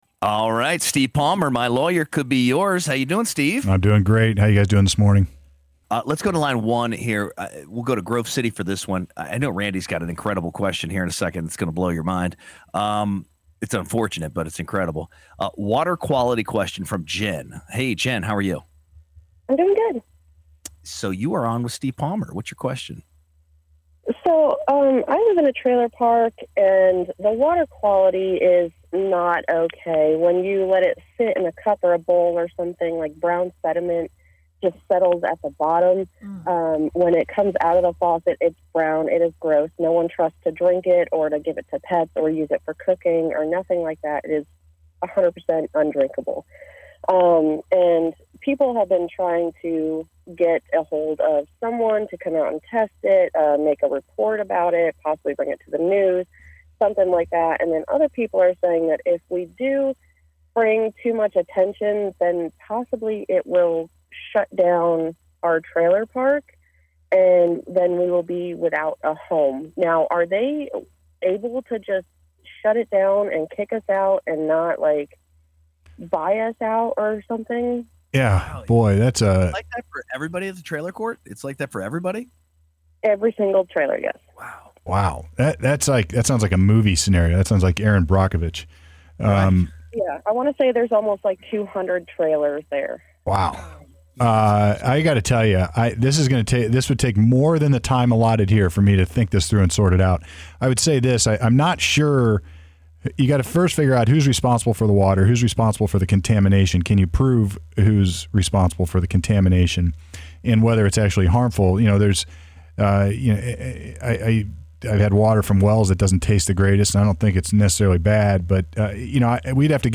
Recorded at Channel 511 .